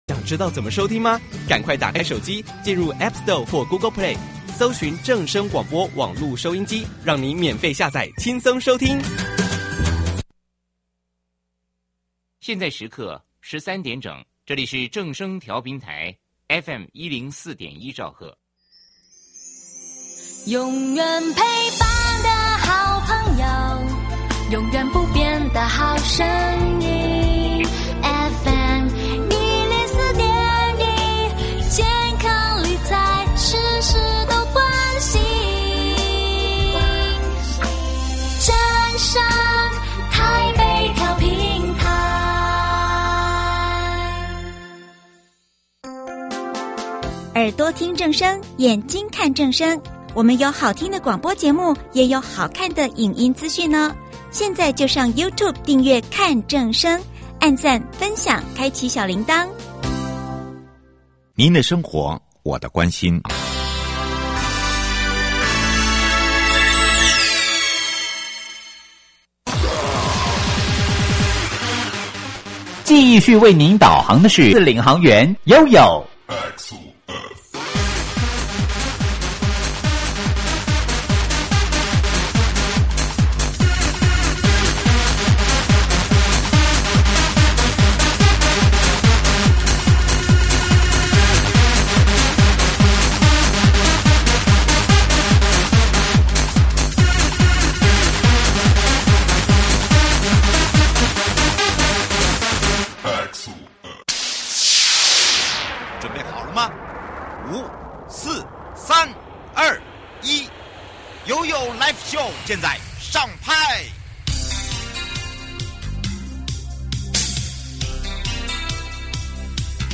節目內容： 國土署都市基礎工程組 台中市政府建設局陳大田局長(五)